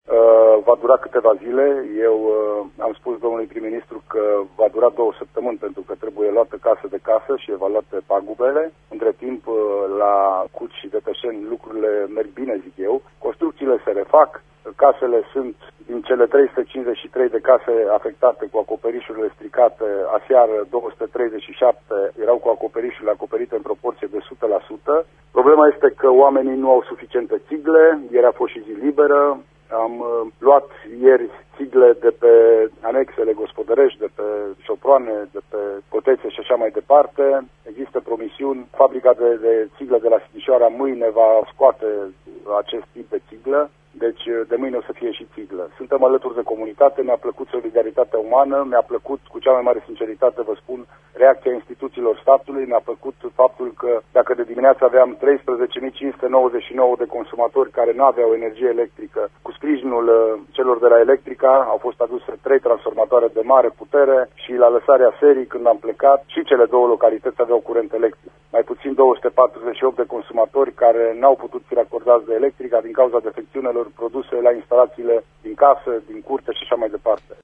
Într-un interviu acordat de Prefectul județului Mureș, Lucian Goga, în emisiunea Sens Unic, a declarat că:
21-iun-prefect-goga.mp3